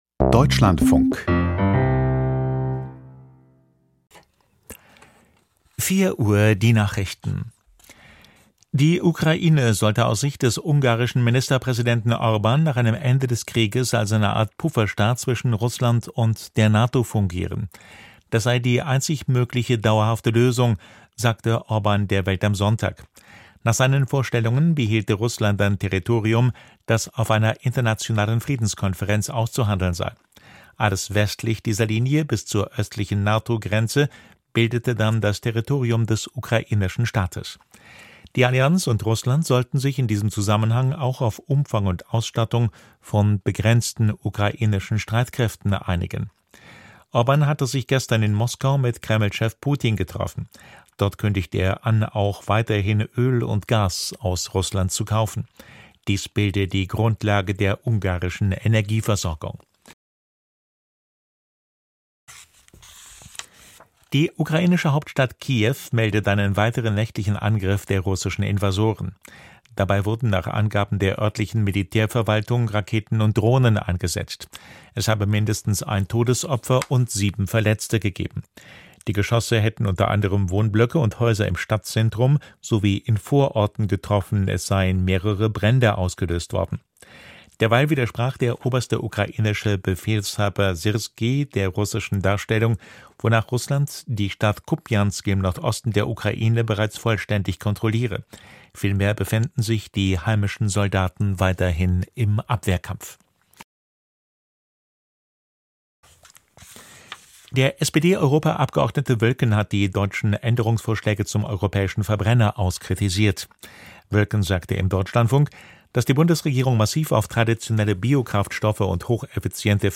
Die Nachrichten vom 29.11.2025, 04:00 Uhr